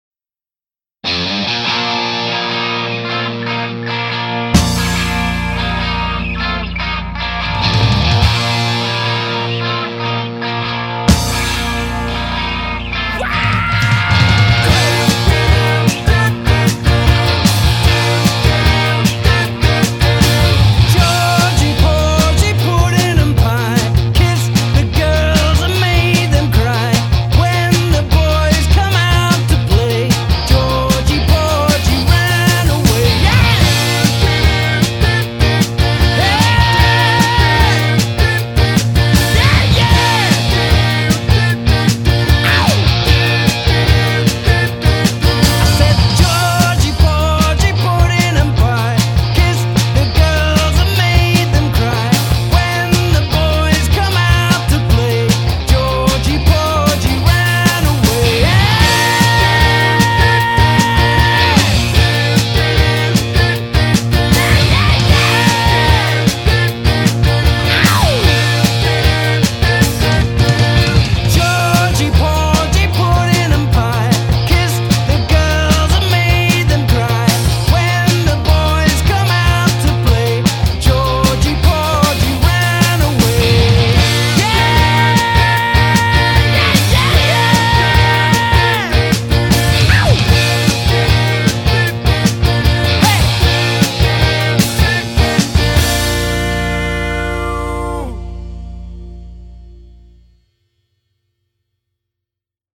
TraditionalRock